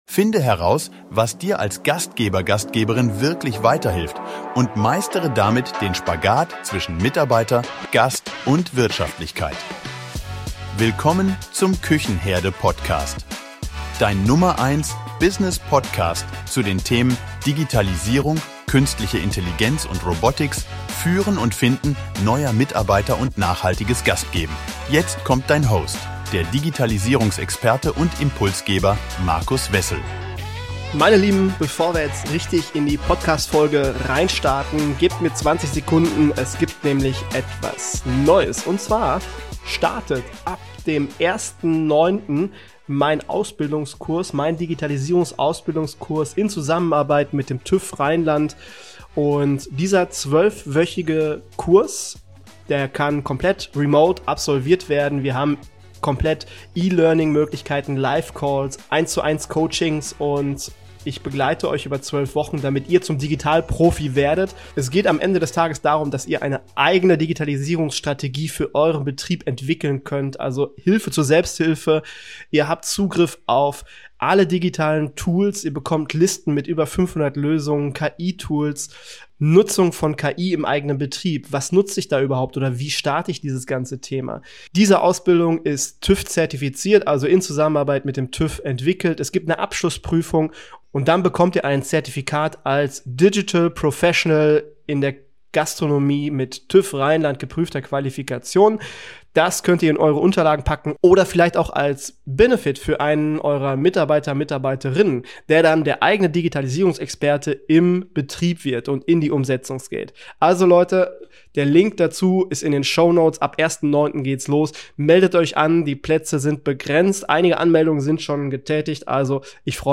Gastro-Podcast